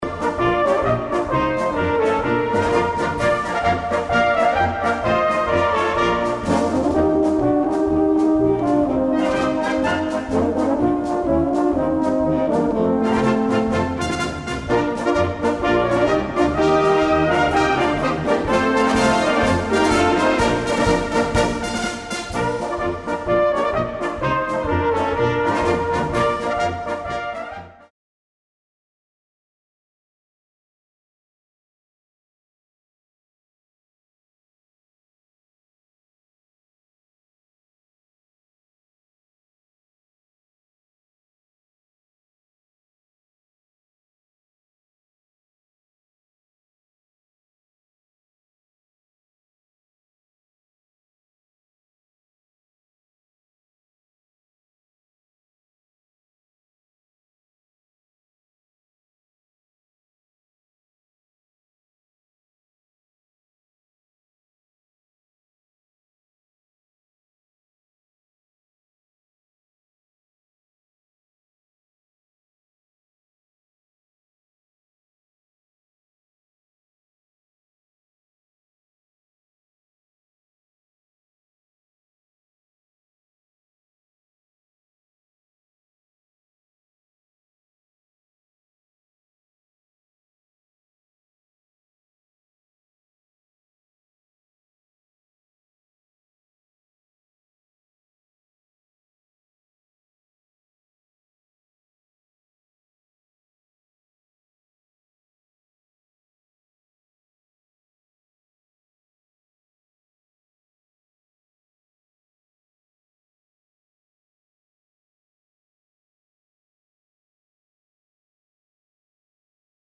Polka´s für Blasmusik